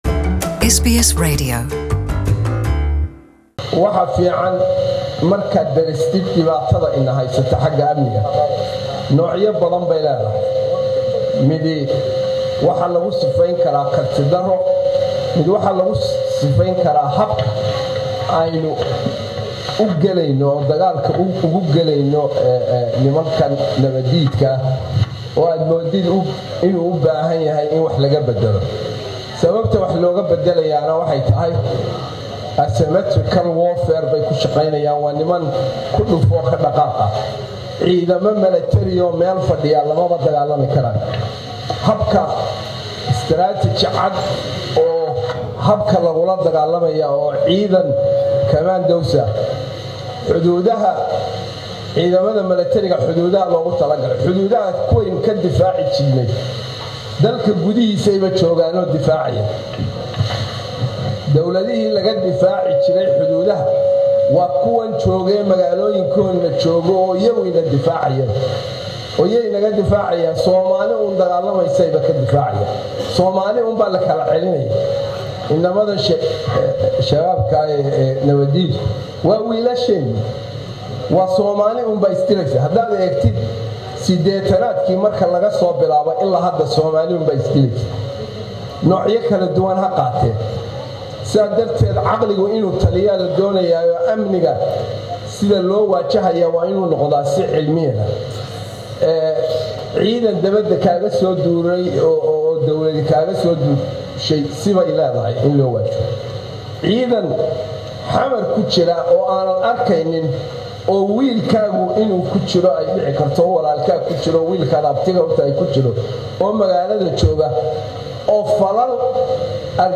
Cali Jamac Jangali MP speech at Parlaiment
Qudbadii xildhibaan Cali Jaamac Jangali